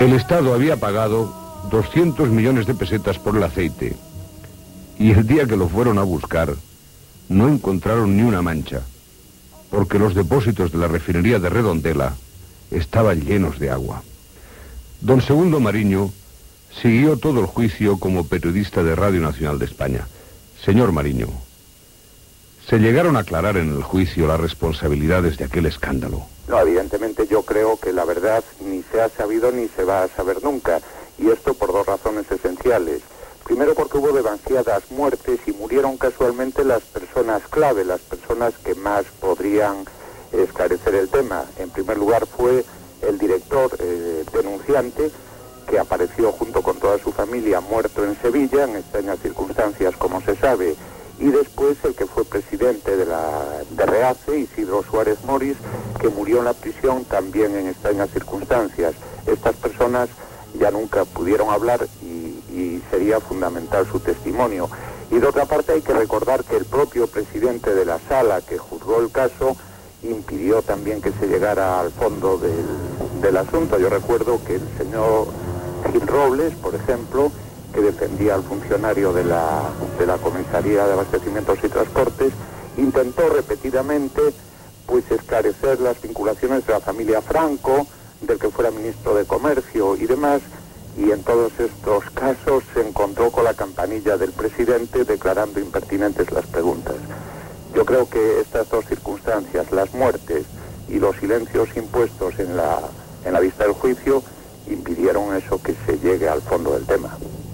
Entrevista
Divulgació